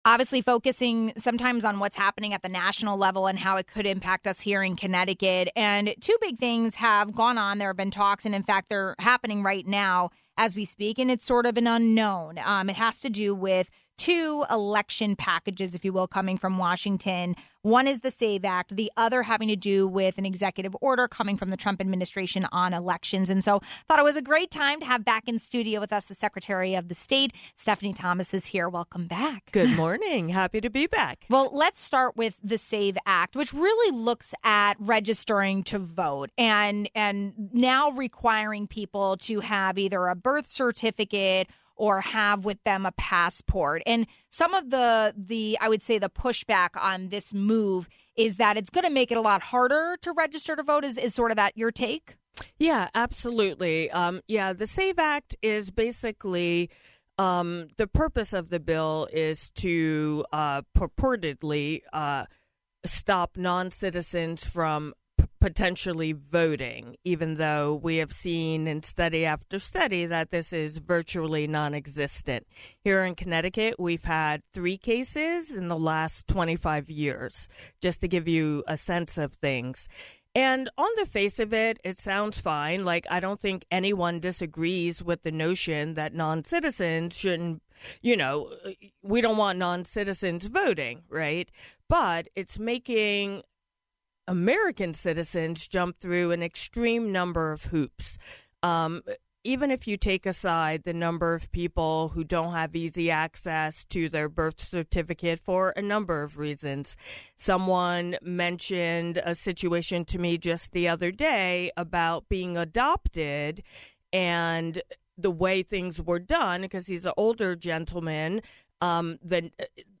There are major moves happening in Washington DC regarding voting and election handling in the country. Those actions include executive orders and the SAFE Act. We talked about the trickle-down effect these could have on Connecticut with Secretary of the State Stephanie Thomas.